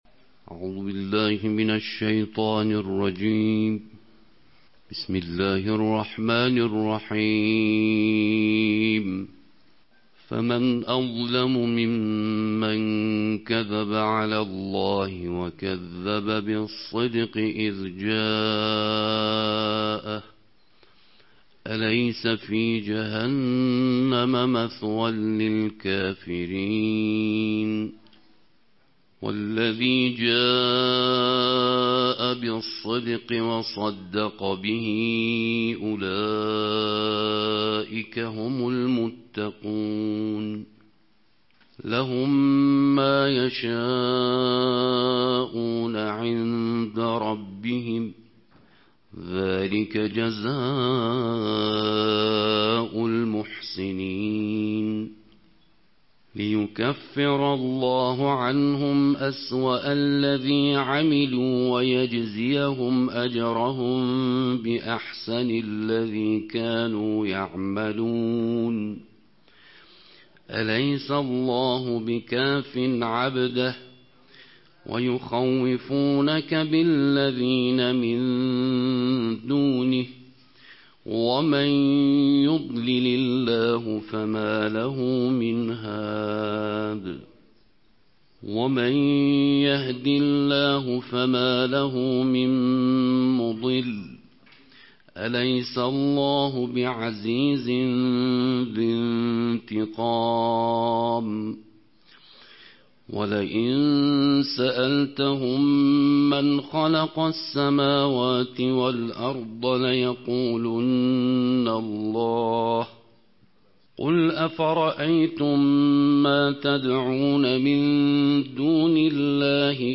Beynəlxalq qarilərin səsi ilə Quranın iyirmi dördüncü cüzünün qiraəti
Quranın iyirmi dördüncü cüzünün qiraəti